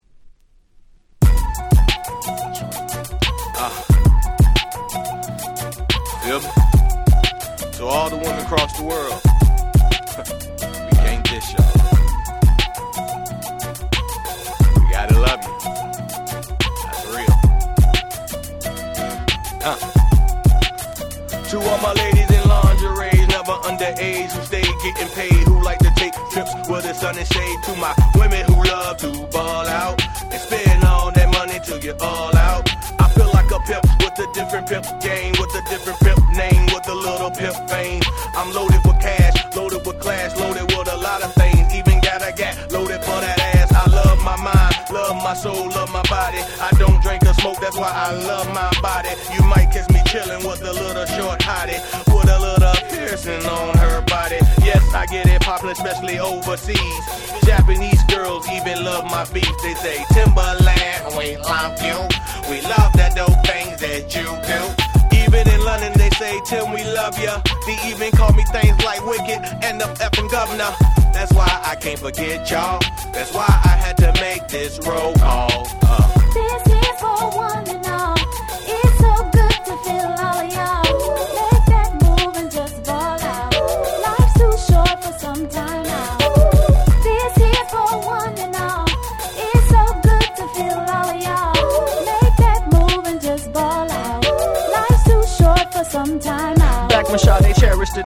01' Smash Hit Hip Hop !!